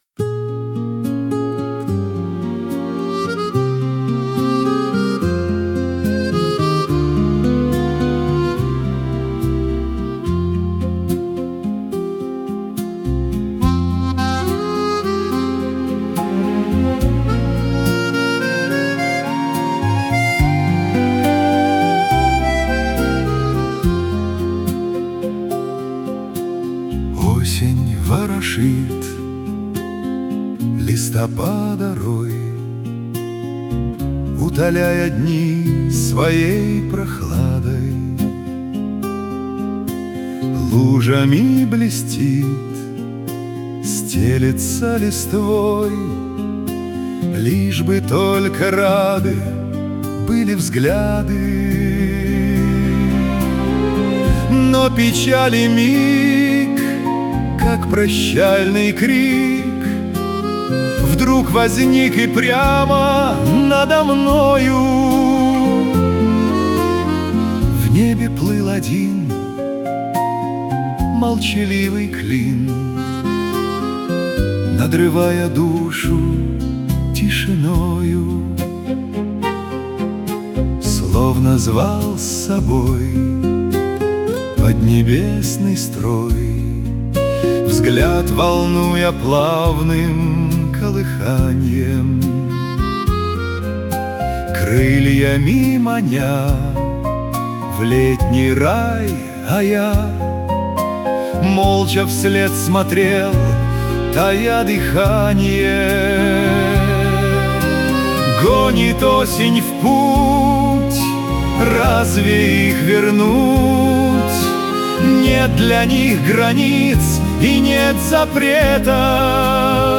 • Жанр: Шансон